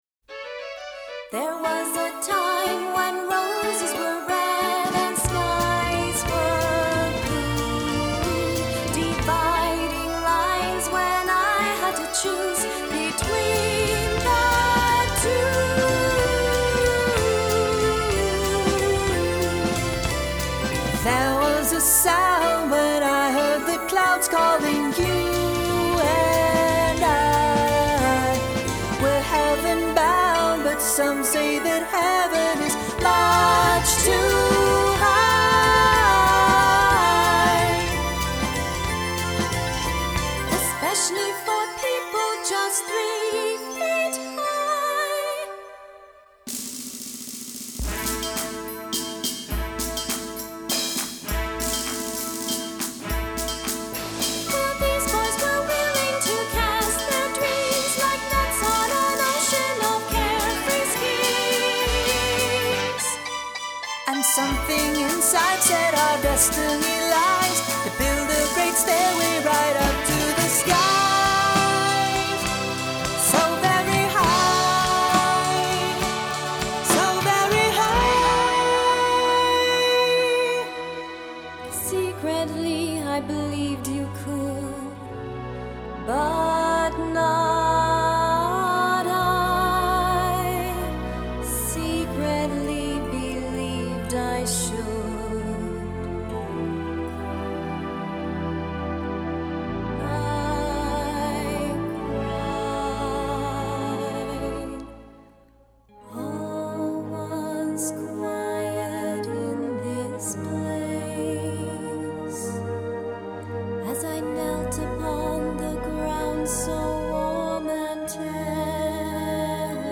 Vocals
Keyboard Orchestration
Guitar